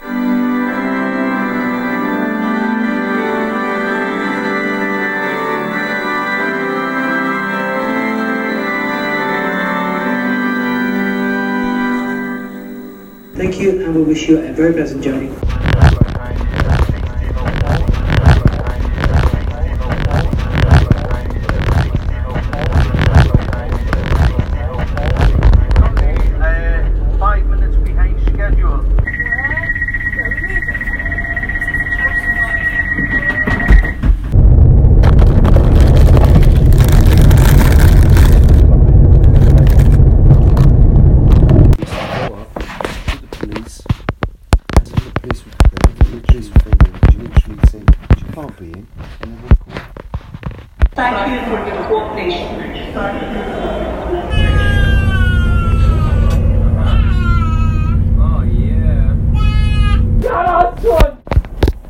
All sounds recorded on tour in UK, 2023.
field recordings in today's UK